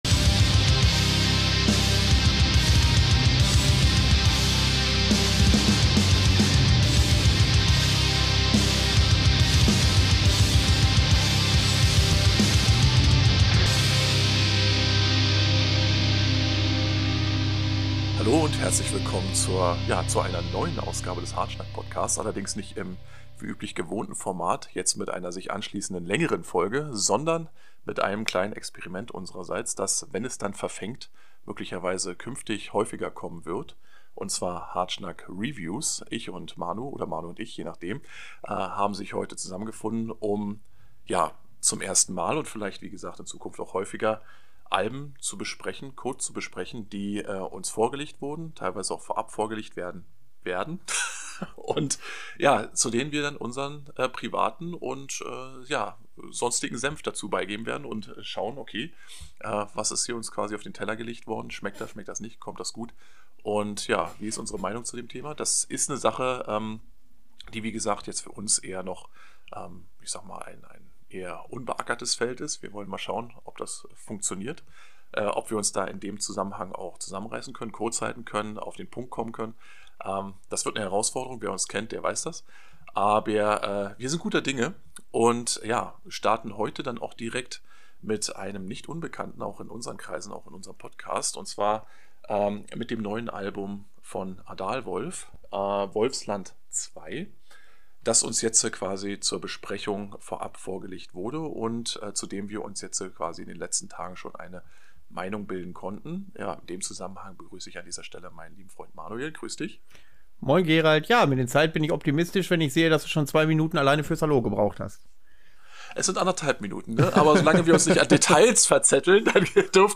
Hartschnack - der Extremmetal Podcast - jeden 2. Sonntag.